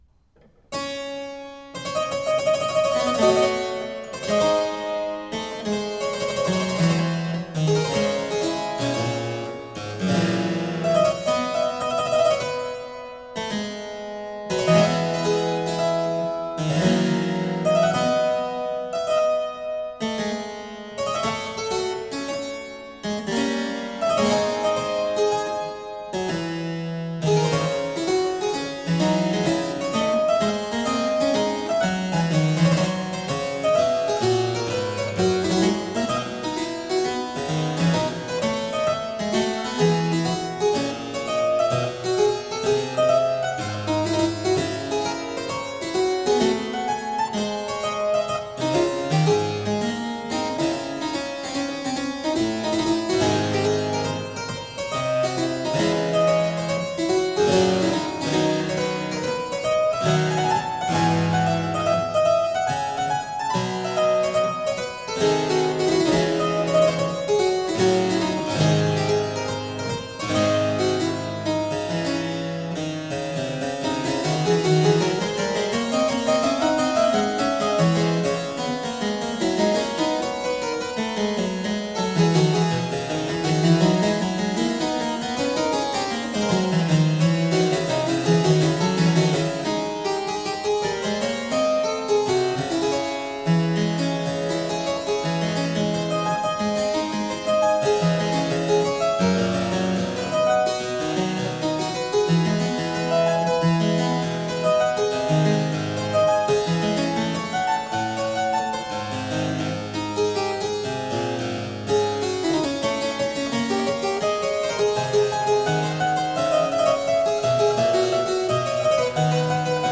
Alessandro Scarlatti - Variations for harpsichord on the theme of Folia
clavicembalo